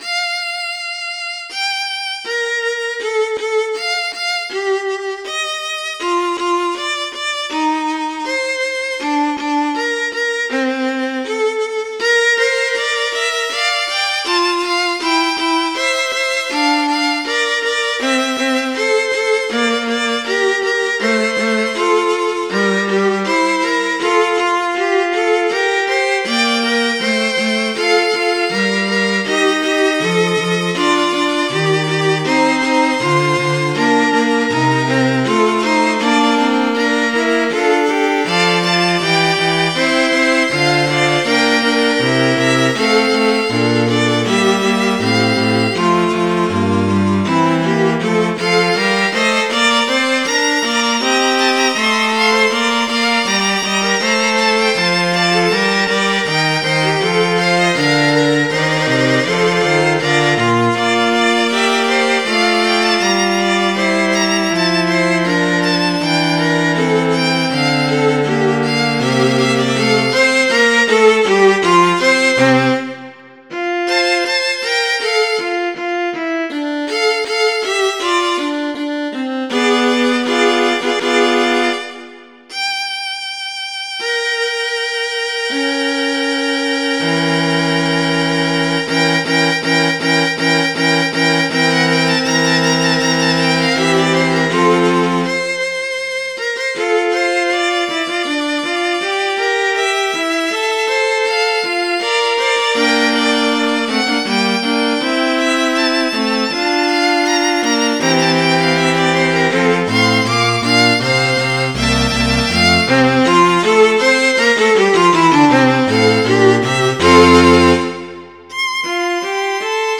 MIDI Music File
String Quartet in B-flat Major Type General MIDI